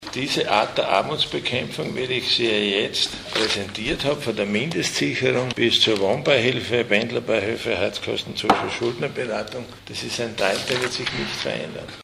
Nachfolgend finden Sie O-Töne aus der heutigen Pressekonferenz:
Antwort LH-Stv. Siegfried Schrittwieser